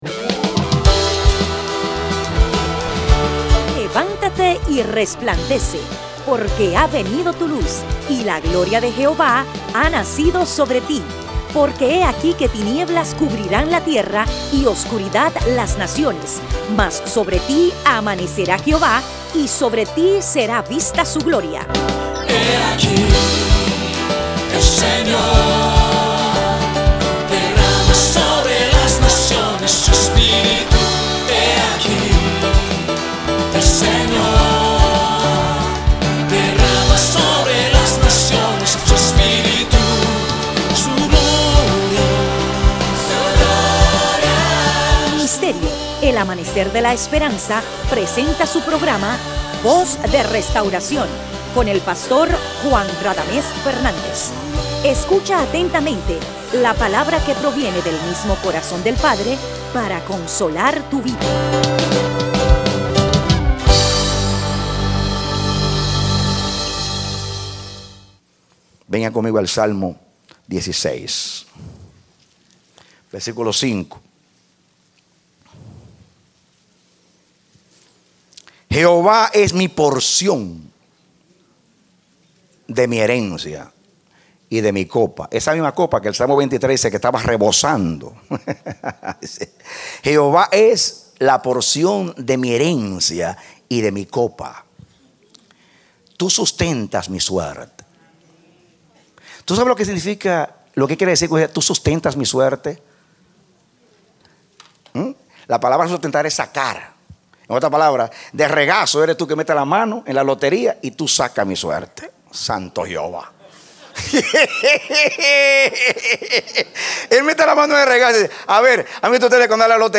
Parte B Predicado Noviembre 25, 2012